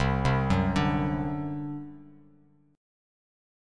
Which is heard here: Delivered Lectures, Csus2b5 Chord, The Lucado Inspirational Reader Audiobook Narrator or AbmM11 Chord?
Csus2b5 Chord